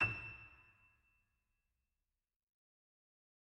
piano-sounds-dev
SoftPiano
e6.mp3